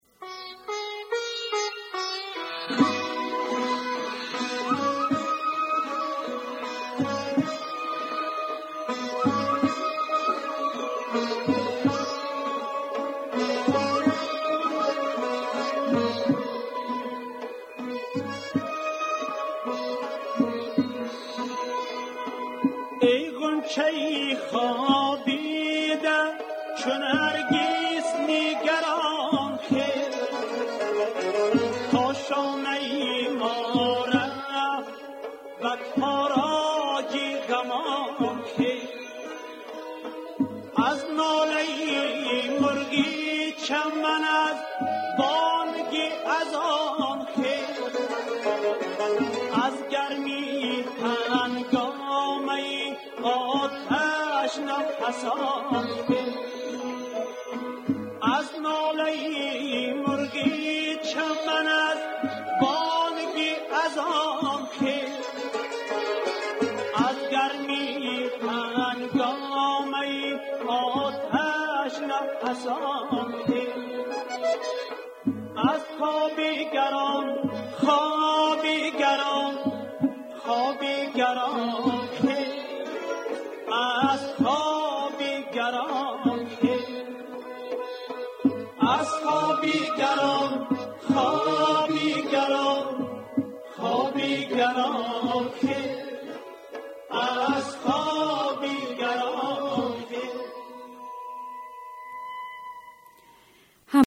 Хоби гарон бо садои хонандаи тоҷик
мусиқӣ